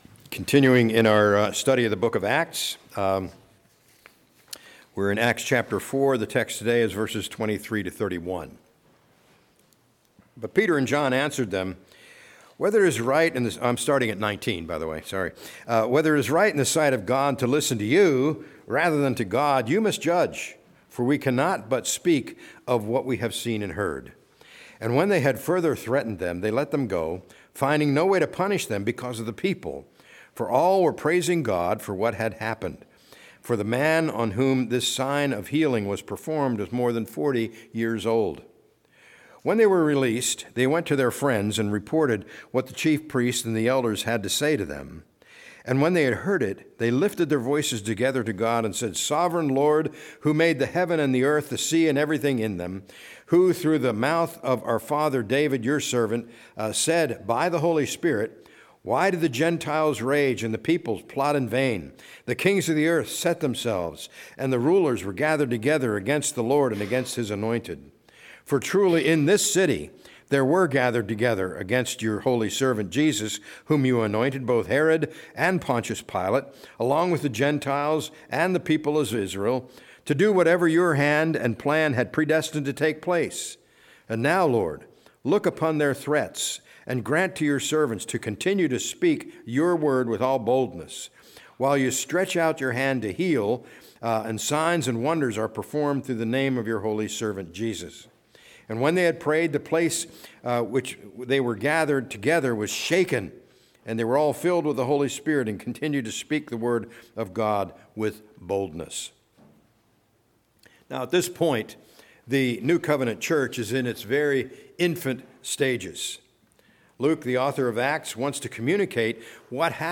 A message from the series "Act 2025."